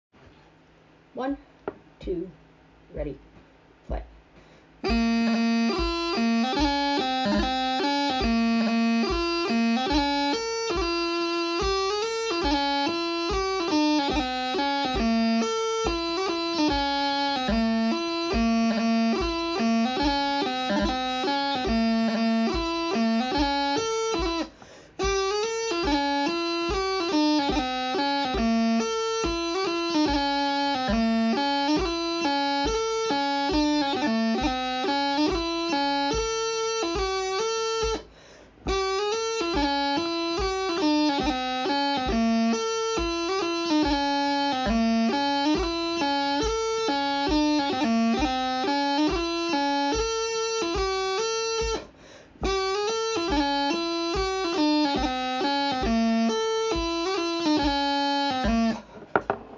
Pipes are currently tuning at 479-480 kHz.
Pipe Audio |Snare Video |